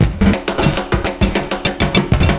100PERCS03.wav